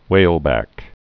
(wālbăk, hwāl-)